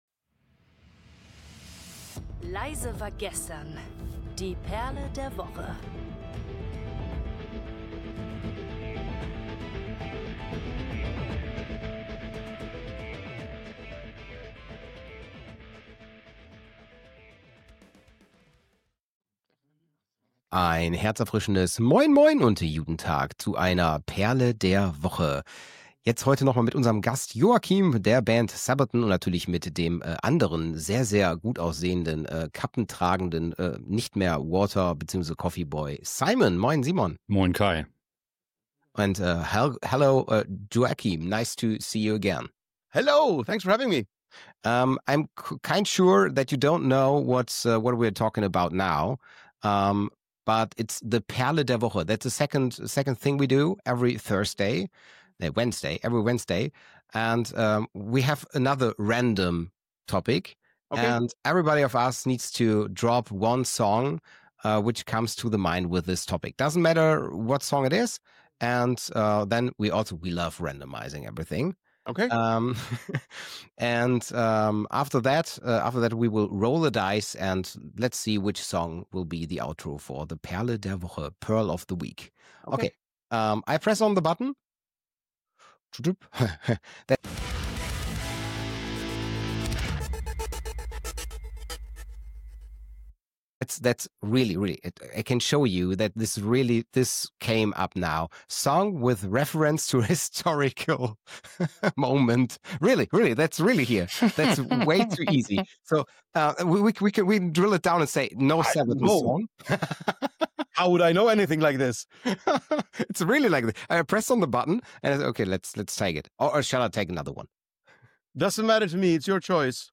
Mit seinem typischen Humor und schwedischem Charme spricht Joakim über die Bedeutung von Geschichte in der Musik, den Einfluss von Iron Maiden auf Sabaton und seine ganz persönliche Verbindung zu „Alexander the Great“.
Das Trio plaudert über Zufälle, musikalische Inspirationen und warum man sich manchmal einfach in einem Thema verlieren muss, bis es ein Song wird.
🎧 05:22 – Outro: Iron Maiden – Alexander the Great